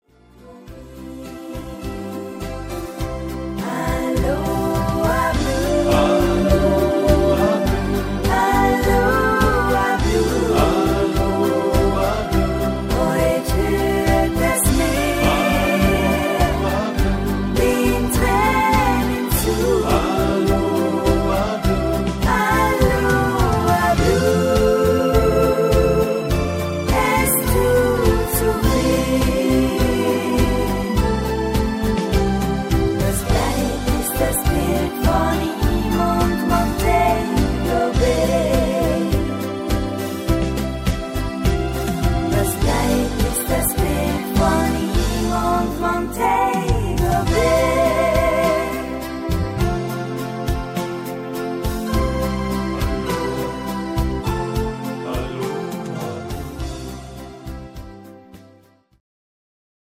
Rhythmus  Medium Slow
Art  Deutsch, Schlager 2000er, Weibliche Interpreten